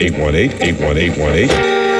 120BPMRAD7-R.wav